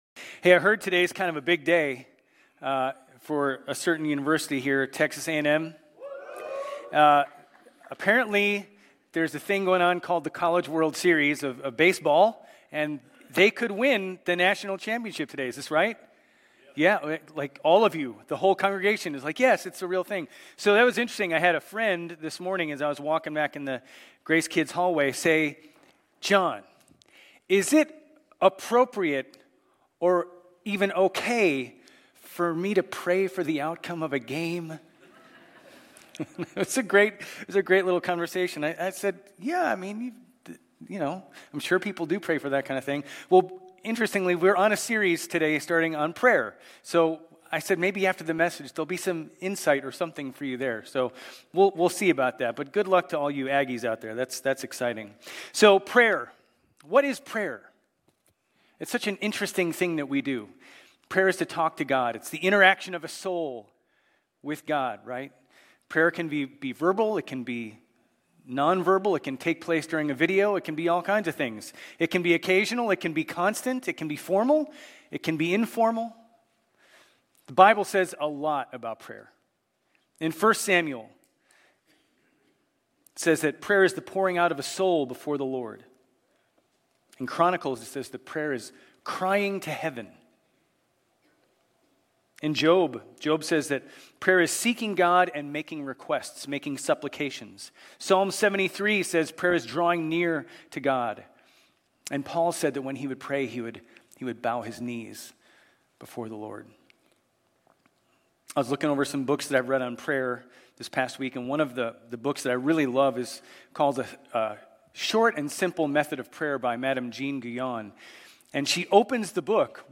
Grace Community Church Old Jacksonville Campus Sermons Psalm 29 - Praise Jun 23 2024 | 00:35:11 1x 00:00 / 00:35:11 Subscribe Share RSS Feed Share Link Embed